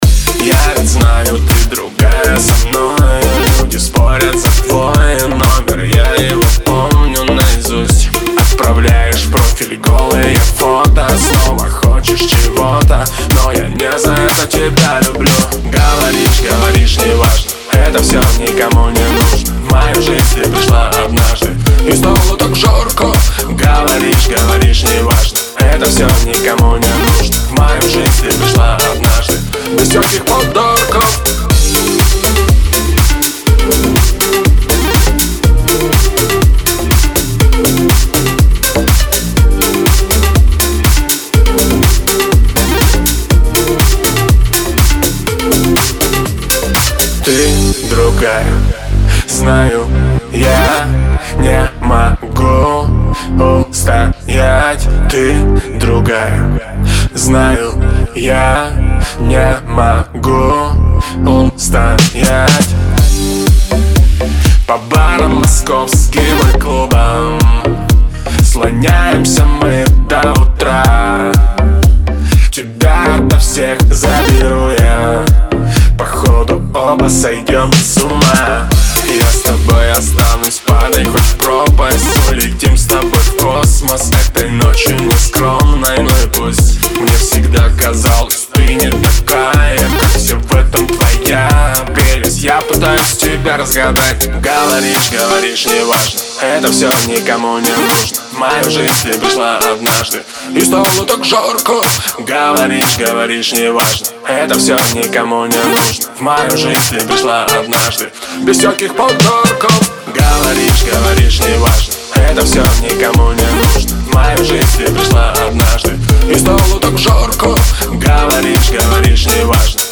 Нажмите для раскрытия... вот что удалось выжать из Rode NTA1.